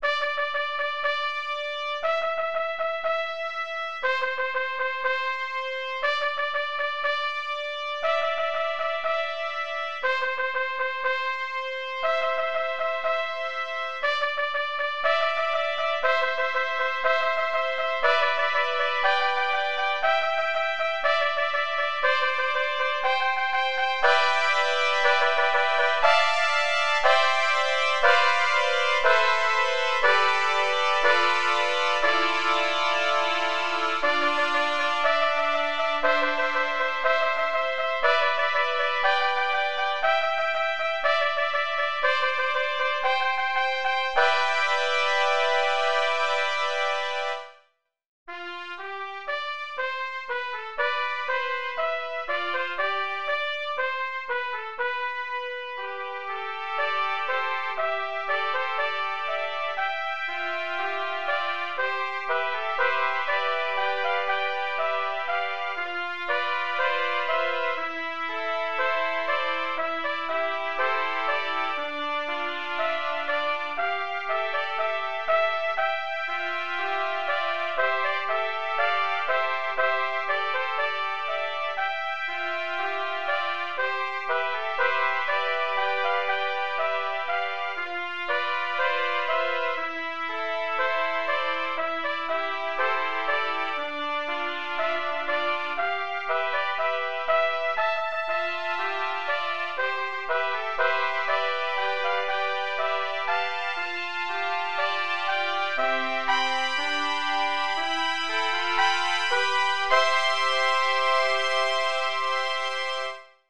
Four Trumpets